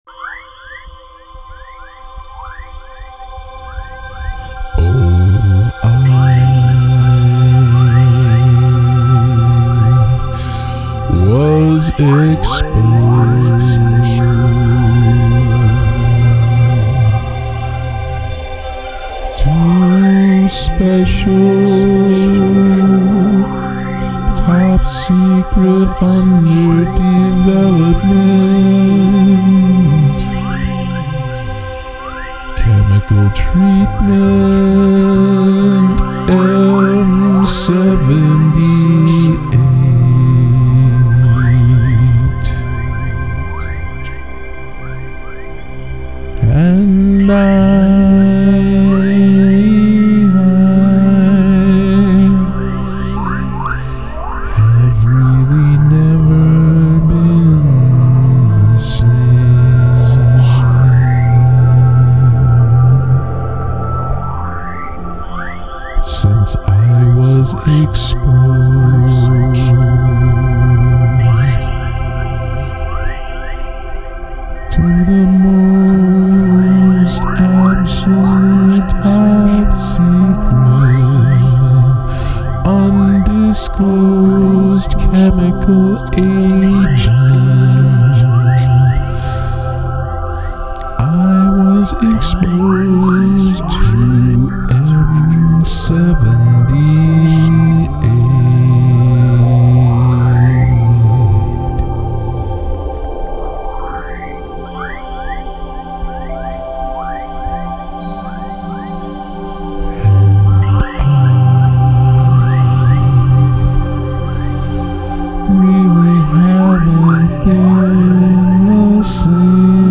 I am trying for that energy here.